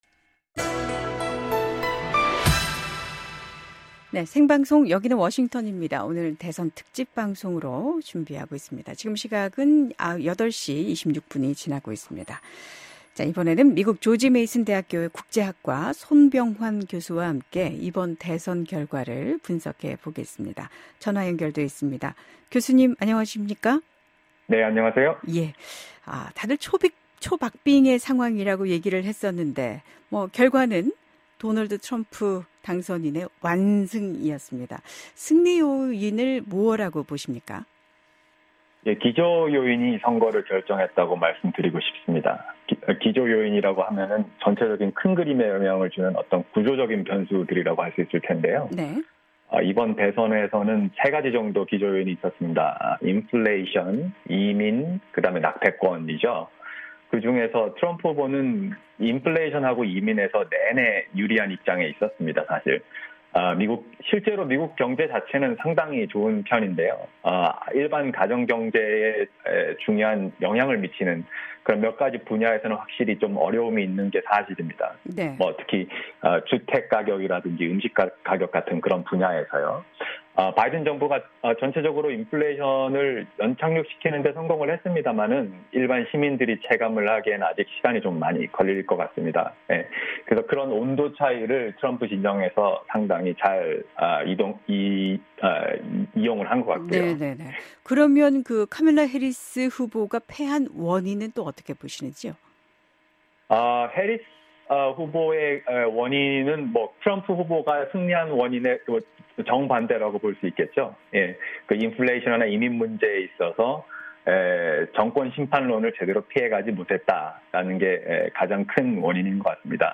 진행자) 생방송 여기는 워싱턴입니다. 오늘 대선 특집 방송으로 준비하고 있습니다.
전화 연결돼 있습니다.